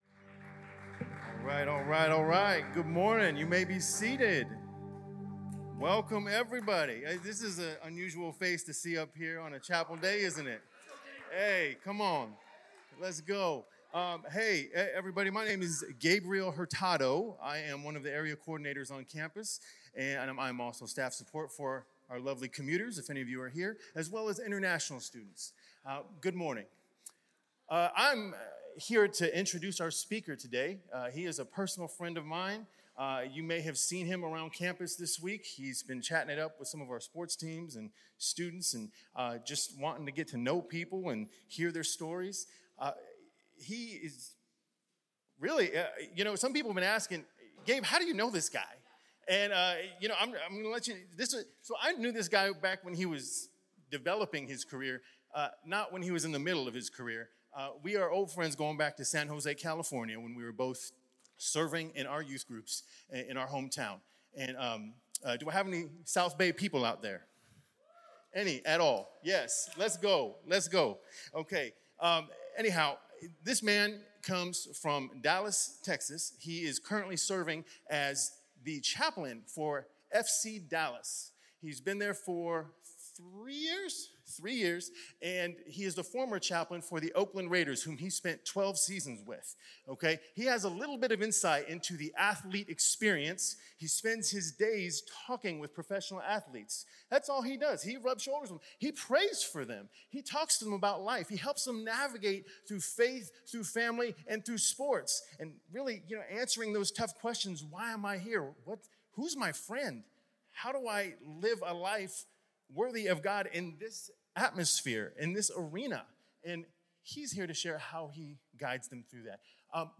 This talk was given in chapel on >Insert day of the week, month day, and year< God Bless you.